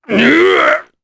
Kaulah-Vox_Damage_jp_03.wav